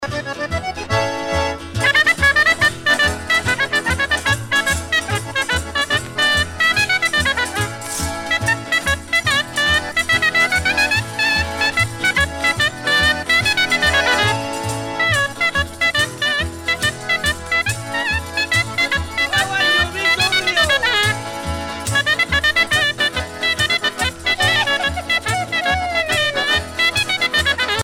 danse : tarentelle
Pièce musicale éditée